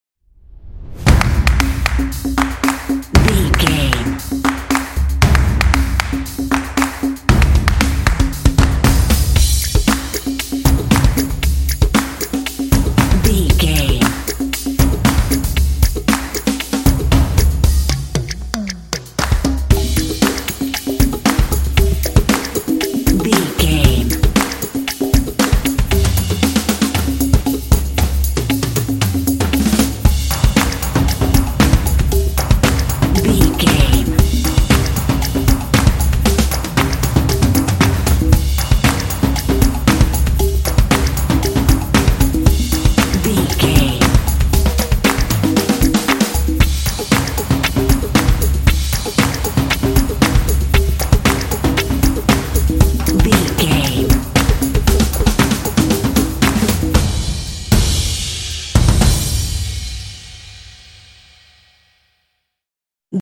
Atonal
driving
motivational
epic
drumline